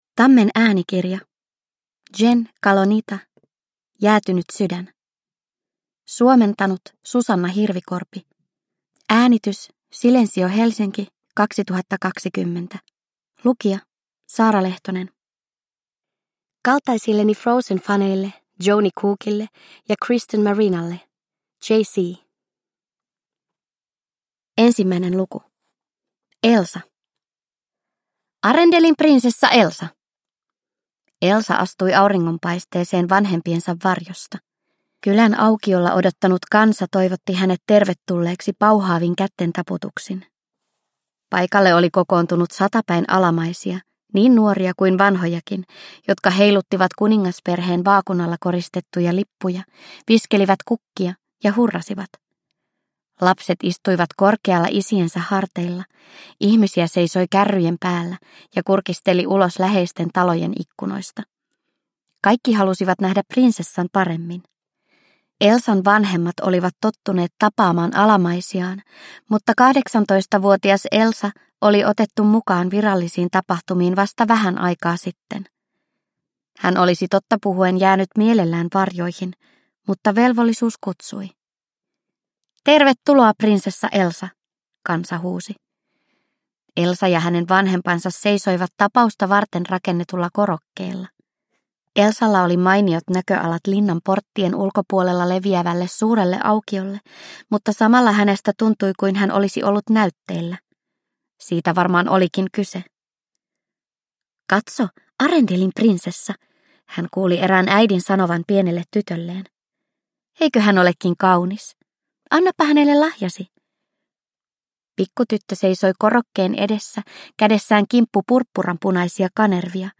Jäätynyt sydän. Twisted Tales – Ljudbok – Laddas ner